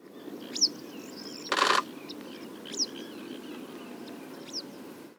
PFR09059, 1-00, 130801, Sand Martin Riparia riparia, calls in flight,
Seelhausener See, Telinga parabolic reflector